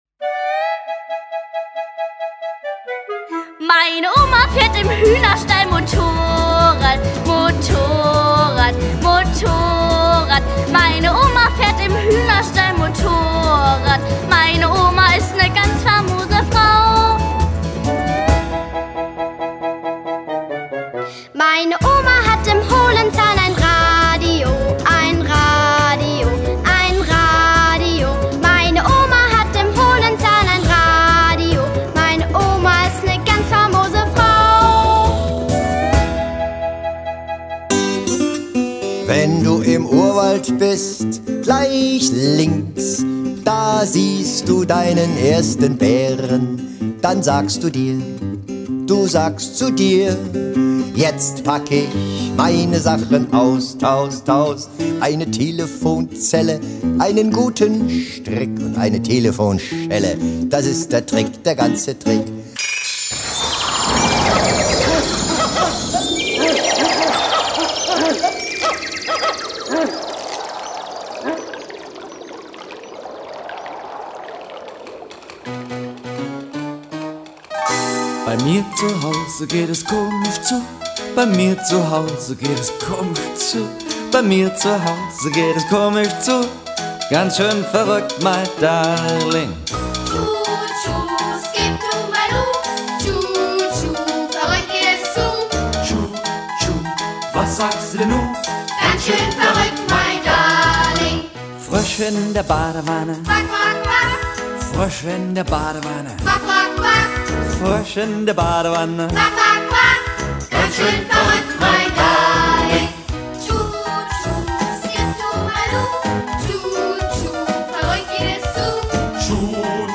Schräge Hits und lustige Lieder für Kinder